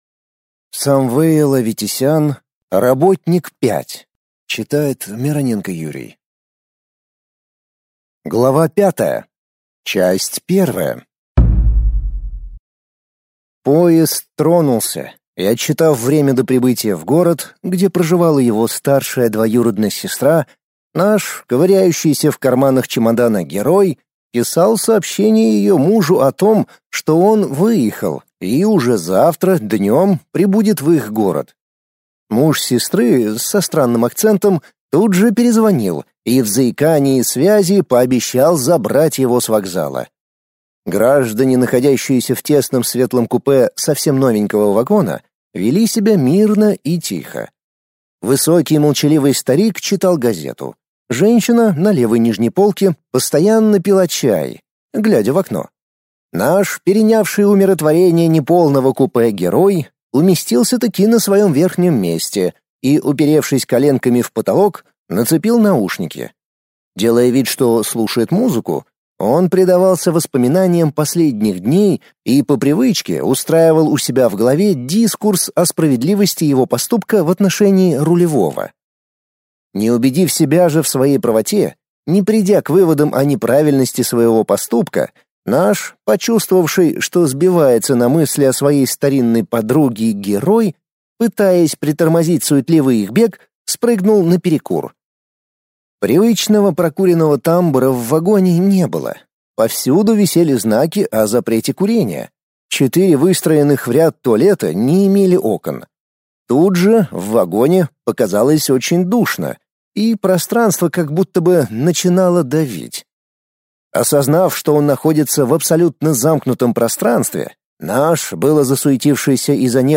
Аудиокнига Работник 5 | Библиотека аудиокниг